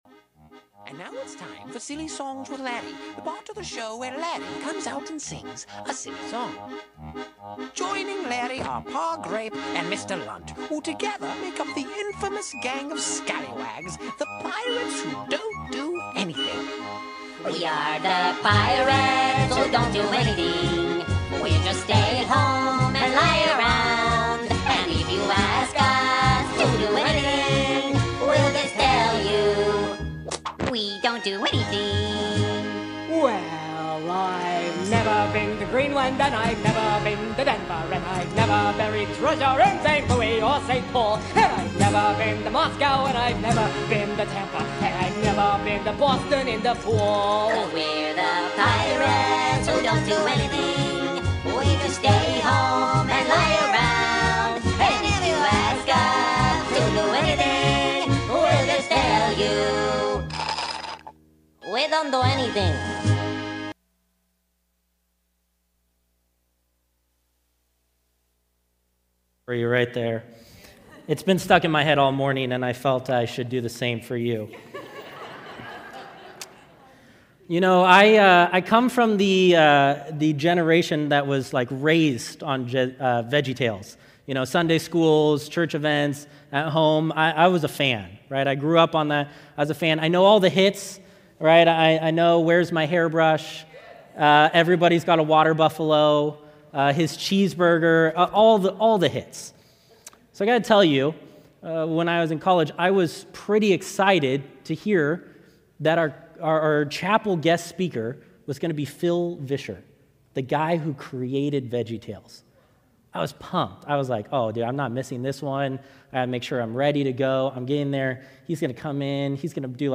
Sermon Archive